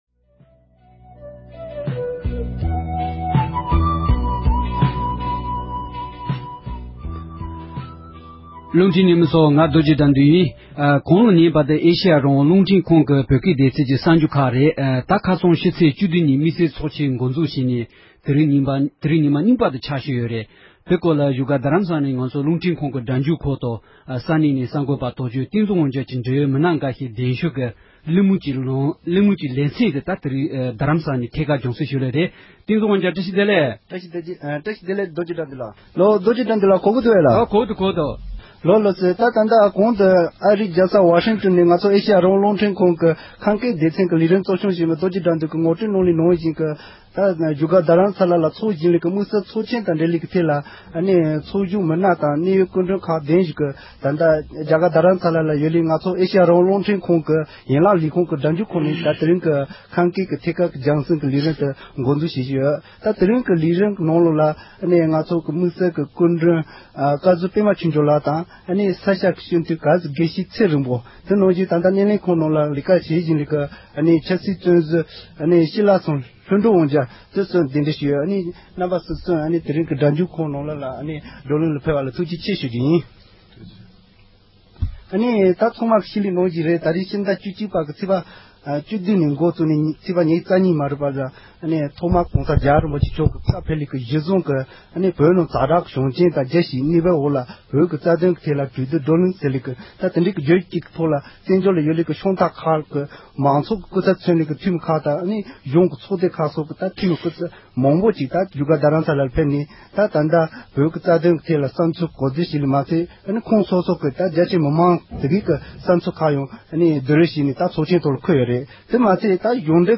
བཞུགས་སྒར་དུ་དམིགས་བསལ་ཚོགས་ཆེན་དབུ་བཙུཌ་གནང་ཡོད་པའི་སྐོར་འབྲེལ་ཡོད་མི་སྣ་ཁག་ལ་འདི་གའི་གསར་འགོད་པས་གླེང་མོལ་ཞུས་པ།